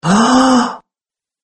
Male Human Gasp Sound Effect Free Download
Male Human Gasp